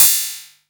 prcTTE47029techno.wav